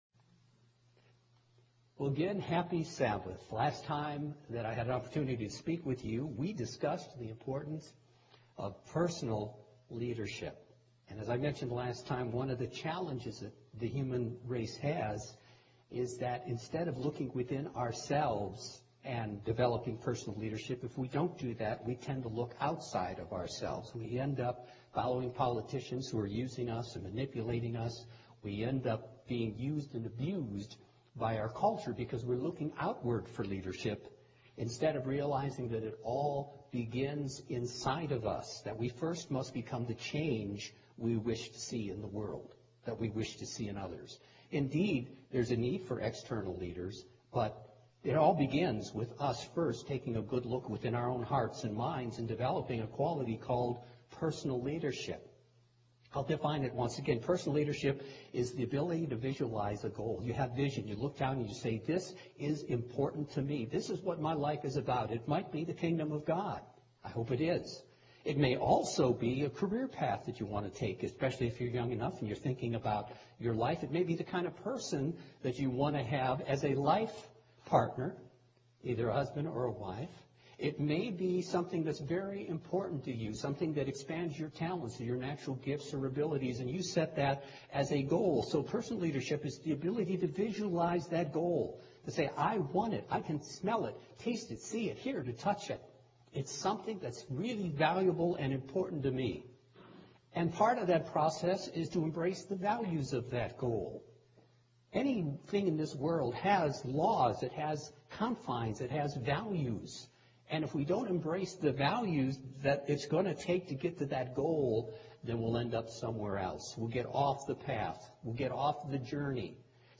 This is the second part of a multipart sermon on the importance of personal leadership. The qualities of leadership must start within each one of us as individuals.